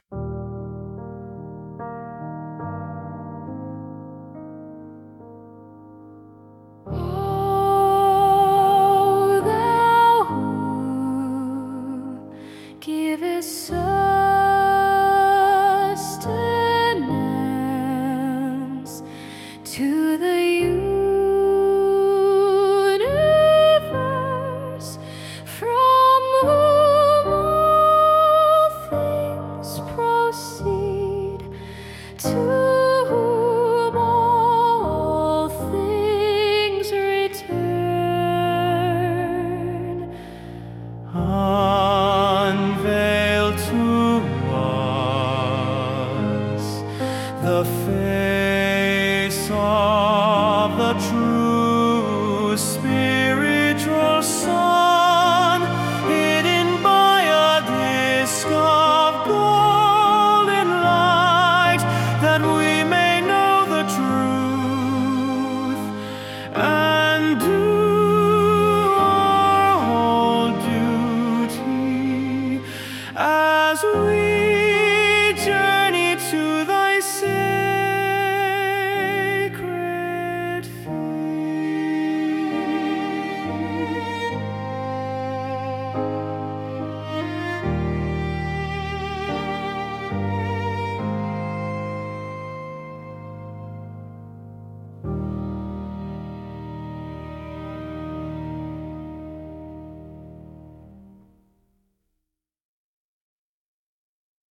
Musicalized mantrams for mindfulness